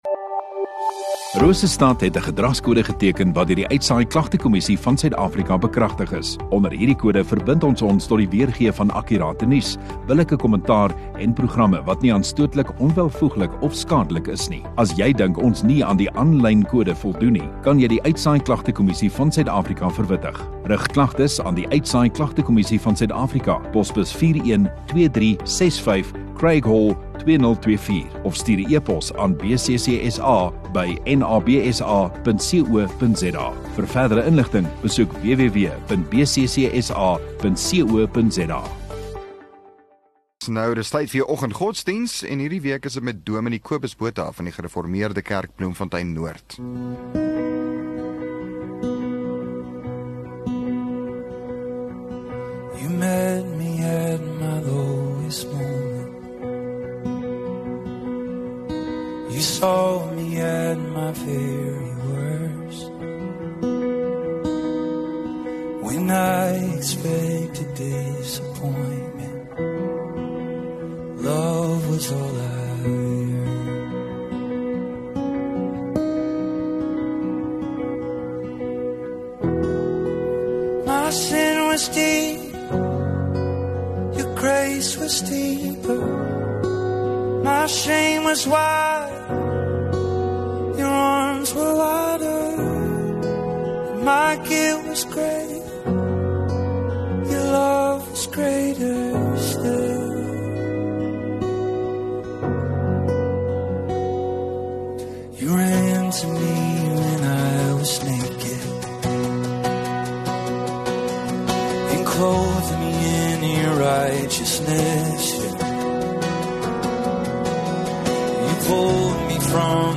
7 Mar Vrydag Oggenddiens